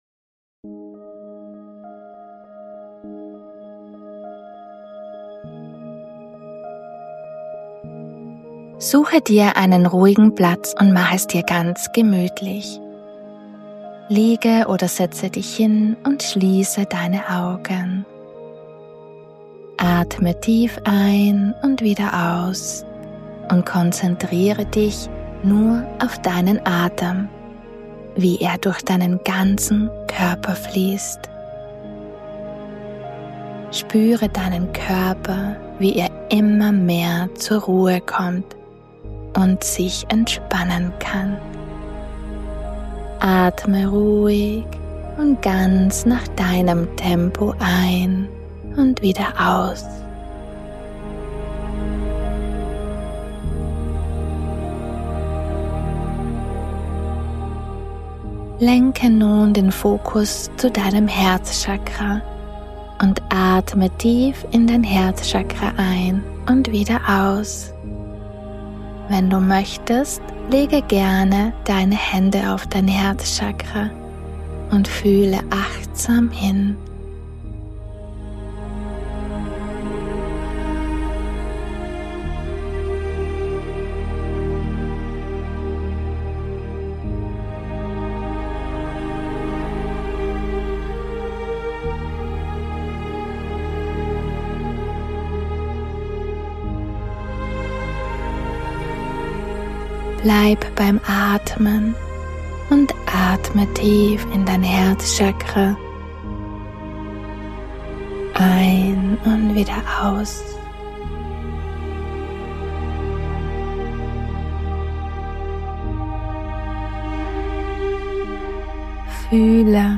061 I Meditation: Herzenswünsche manifestieren ~ Intu Soul - Der Podcast